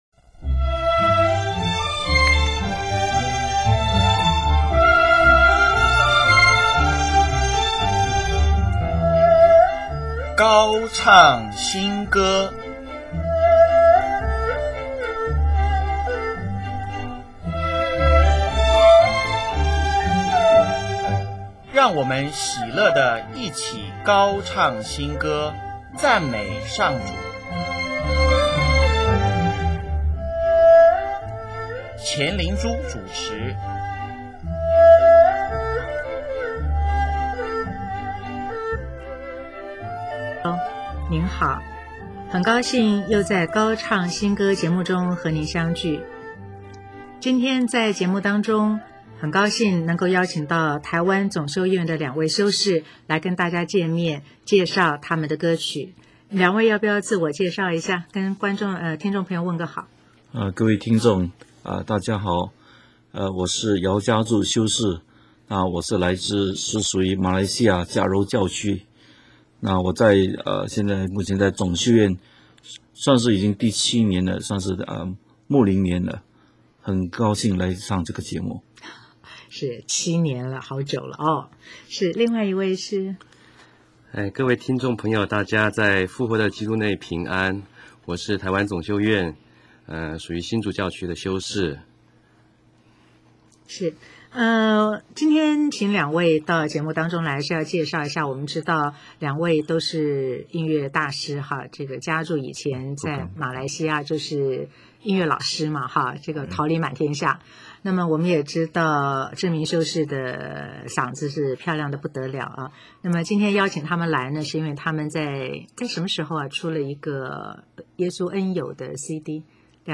修士们总共录十首歌，每一首都录五、六遍，每一首都不经剪接，完全是一气呵成地收录。有人认为，他们唱得不甚专业，修士们也明白，因为他们是以祈祷的心情在唱歌，希望听友带著一颗心，跟修士们的歌声，一起祈祷。